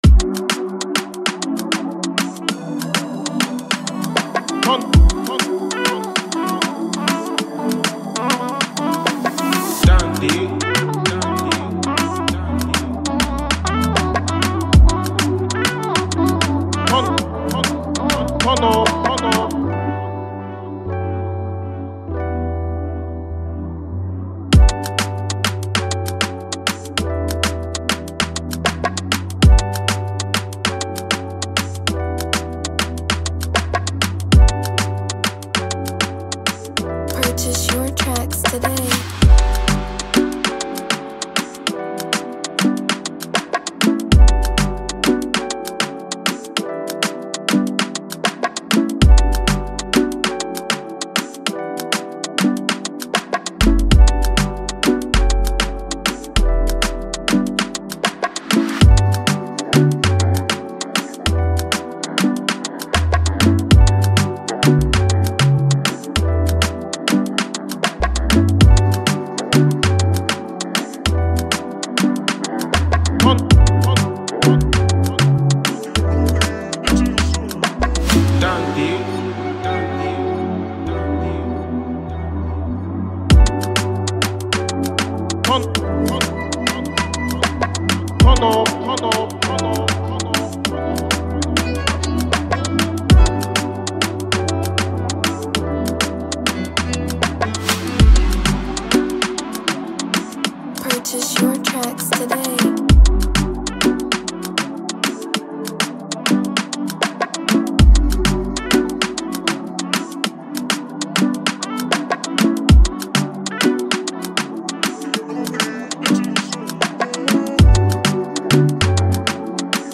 Amapiano beat